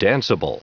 Prononciation du mot danceable en anglais (fichier audio)
Prononciation du mot : danceable